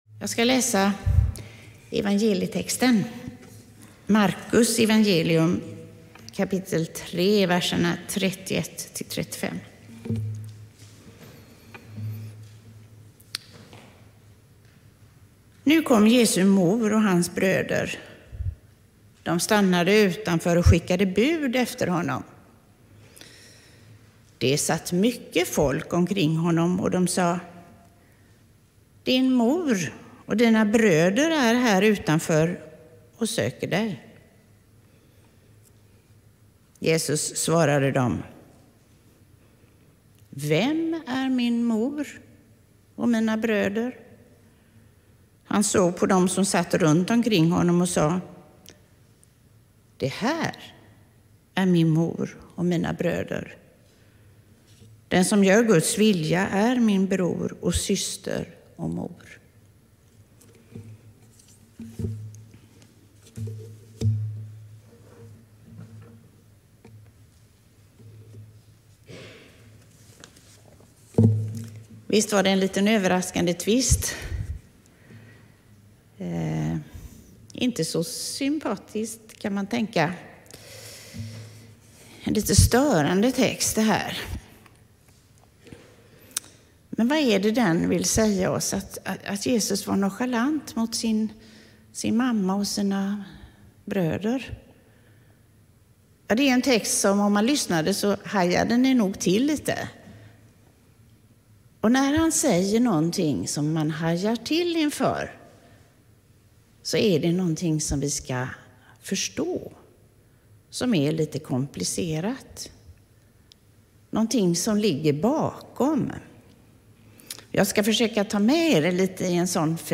Predikan, 2023-03-19